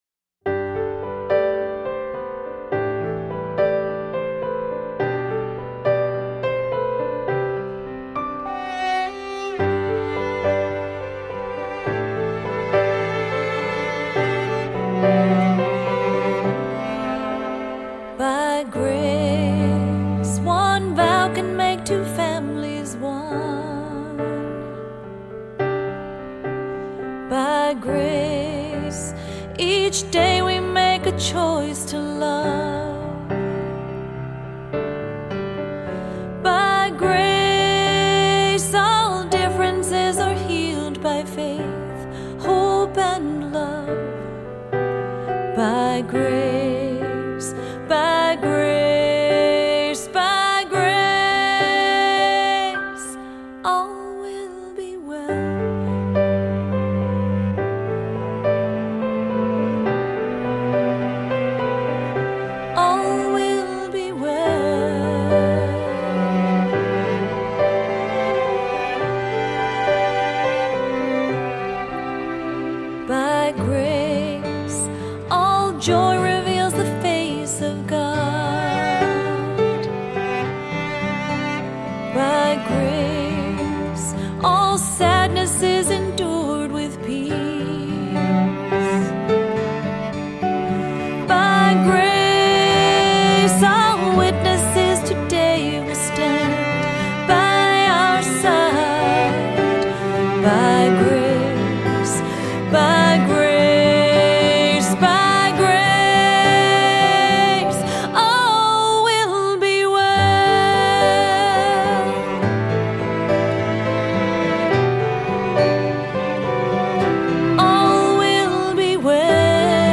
Voicing: Unison; Solo